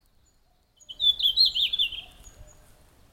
elev. 710 m. At that time, the flies that would annoy us so much during the day were still sleeping.
bluetail-red-flanked002-Tarsiger-cyanurus.mp3